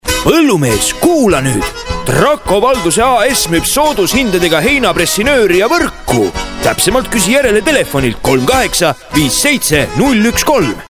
Professioneller estnischer Sprecher für TV / Rundfunk / Industrie.
Sprechprobe: Industrie (Muttersprache):
Professionell voice over artist from Estonia.